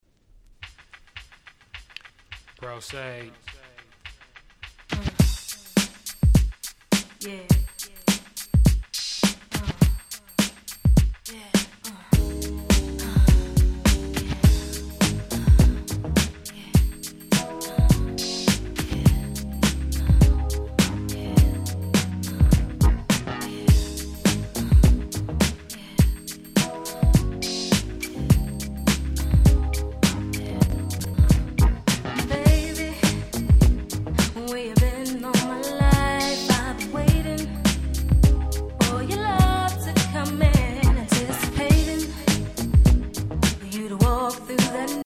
Nice Hip Hop Soul !!